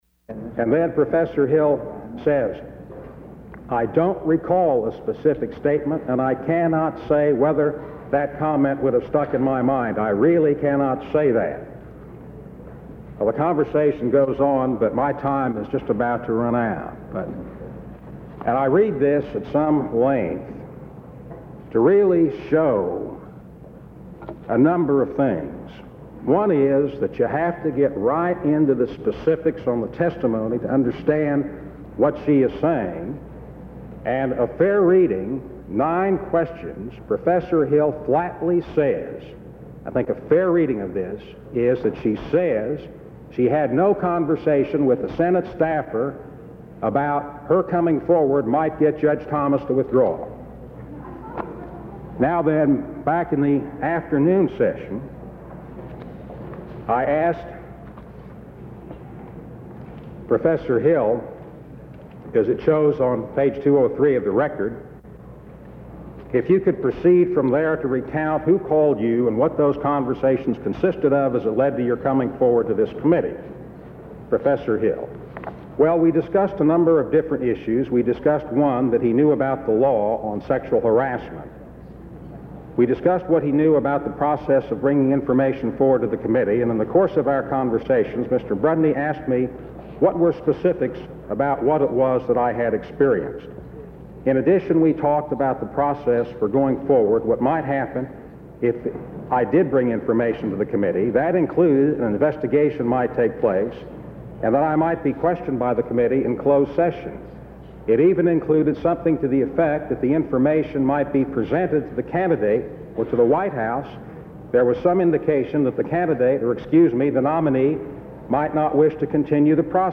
Part of the U.S. Senate debate on the Clarence Thomas appointment to the United States Supreme Court centering on the validity of the testimony of Anita Hill
Senate Judges--Selection and appointment United States Material Type Sound recordings Language English Extent 01:05:23 Venue Note Broadcast 1991 October 15.